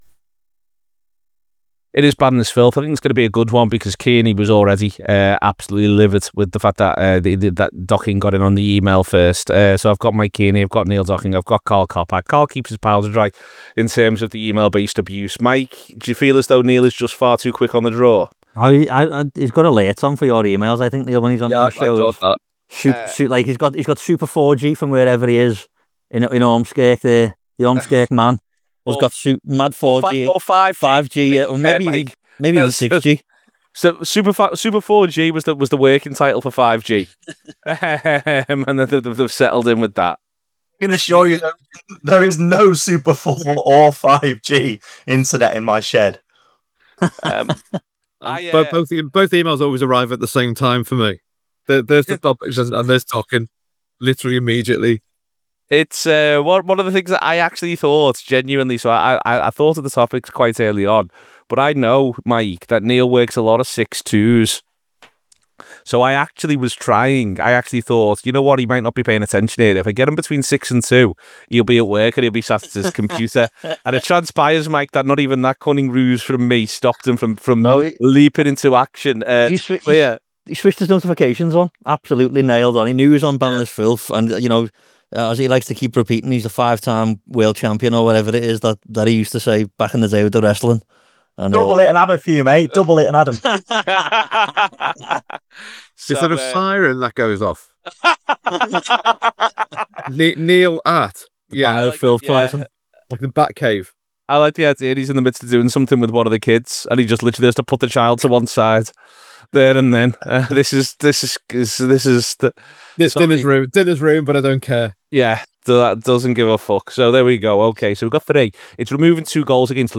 Below is a clip from the show – subscribe for more on which